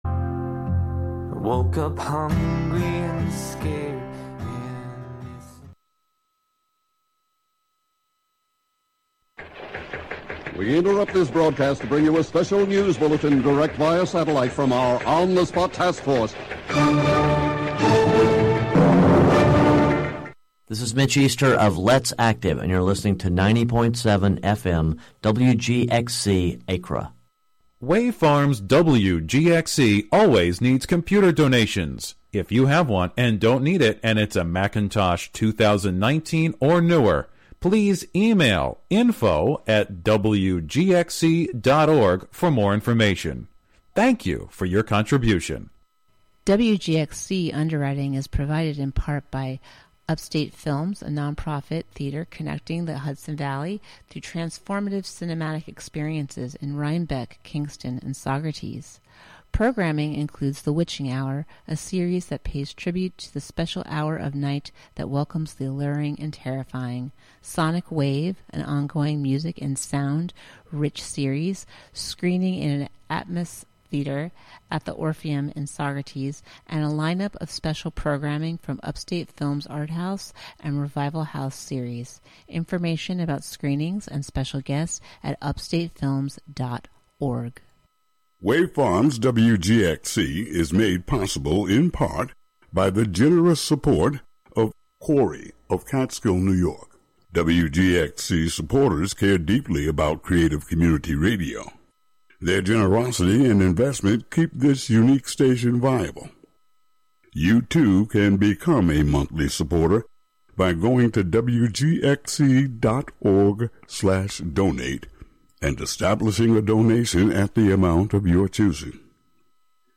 Playing Favorites: guitar music: electric & acoustic (Audio)